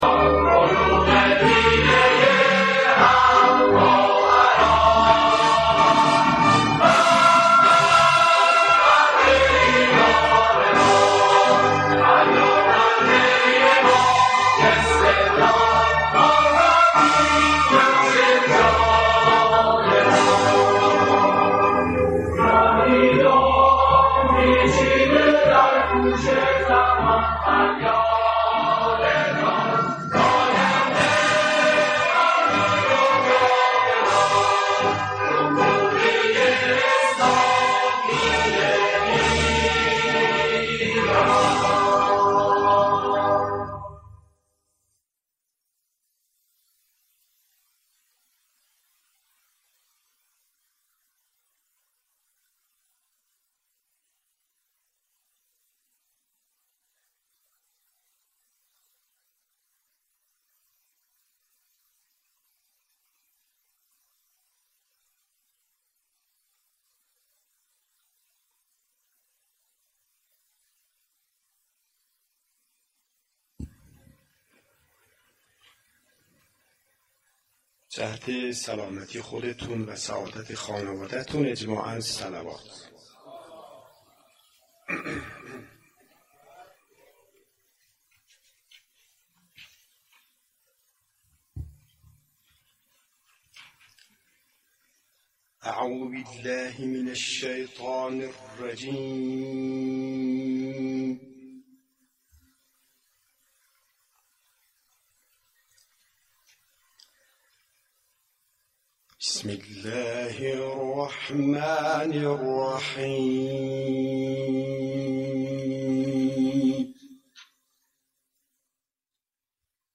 مجمع عمومی عادی بطور فوق العاده شرکت صنایع پتروشیمی دهدشت - نماد: دهدشت (شدهدشت)